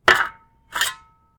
default_shovel_steel3.ogg